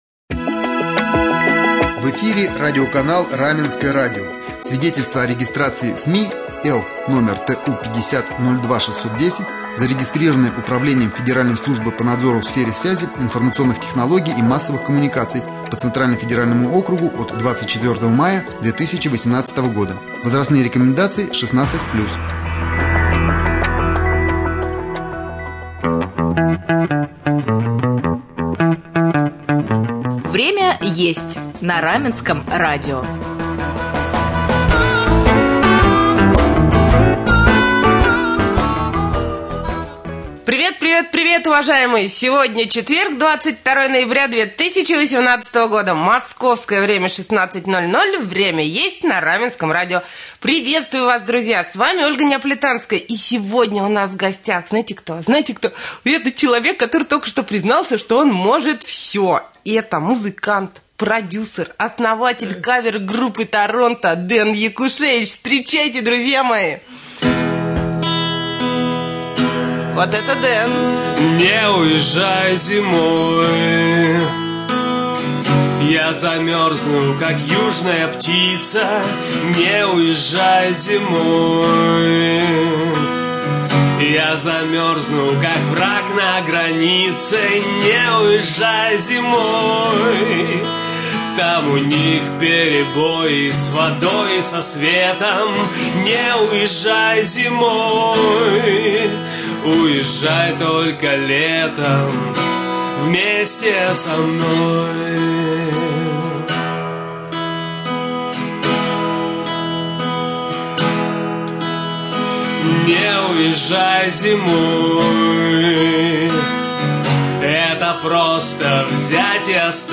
Гостем студии стал музыкант